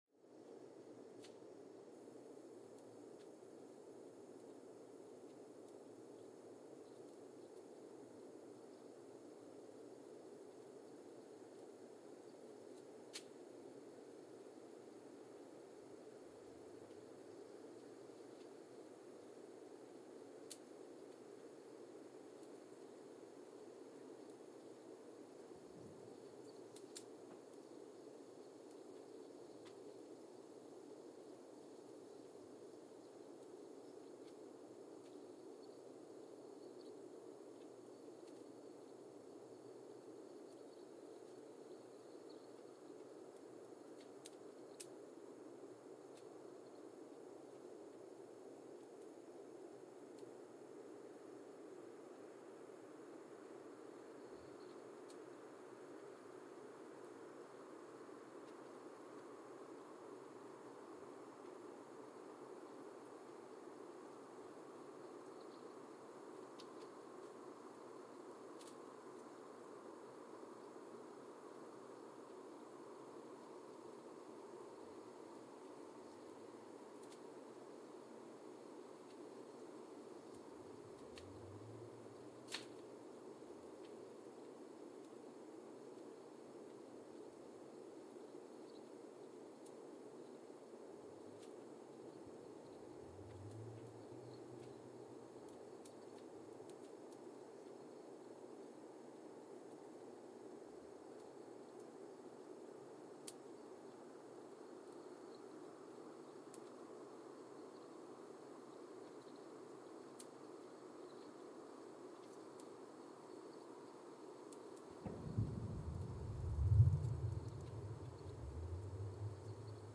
Sensor : Geotech KS54000 triaxial broadband borehole seismometer
Recorder : Quanterra Q330 @ 100 Hz
Speedup : ×1,800 (transposed up about 11 octaves)
Loop duration (audio) : 05:36 (stereo)
SoX post-processing : highpass -2 90 highpass -2 90